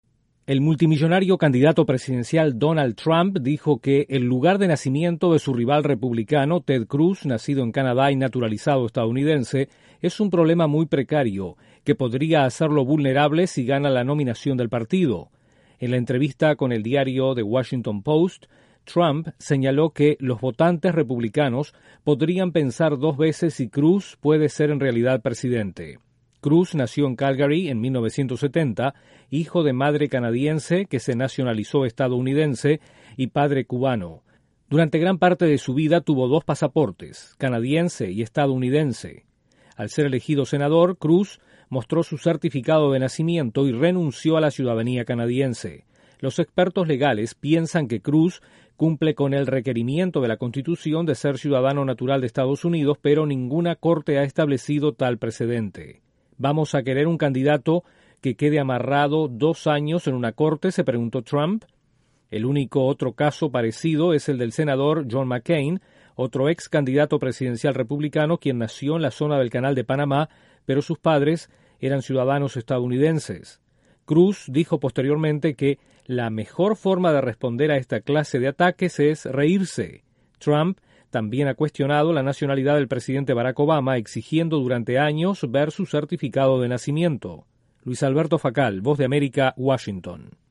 El candidato presidencial estadounidense Donald Trump cuestiona la elegibilidad de su rival republicano Ted Cruz. Desde la Voz de América en Washington informa